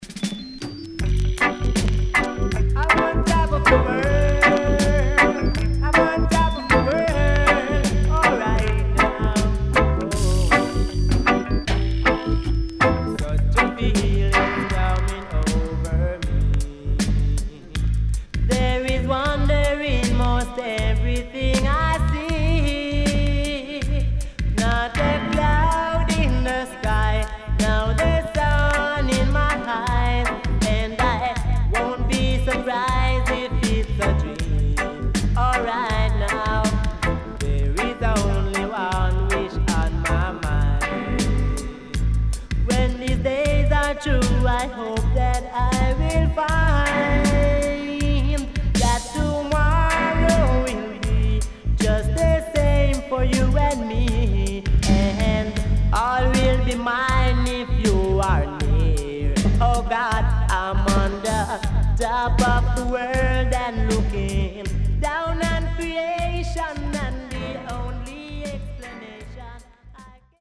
SIDE AA:全体的にチリノイズが入ります。